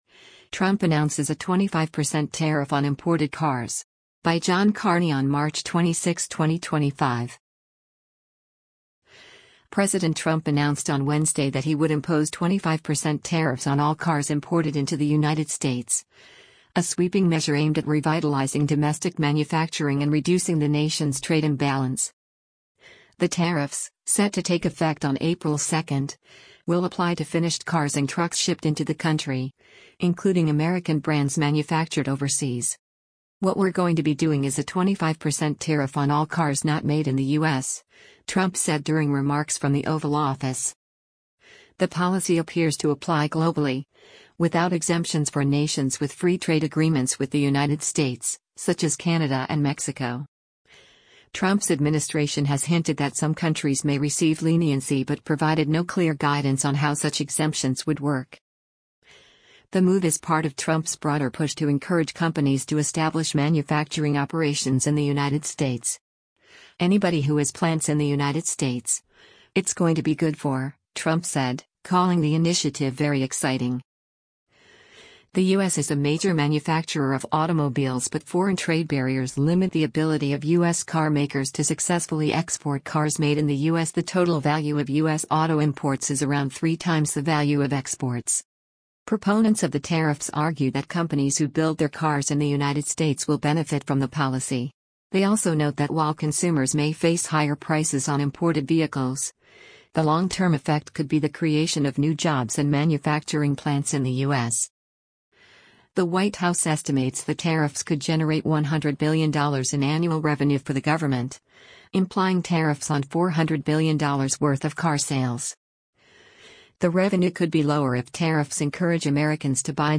US President Donald Trump speaks in the Oval Office of the White House in Washington, DC,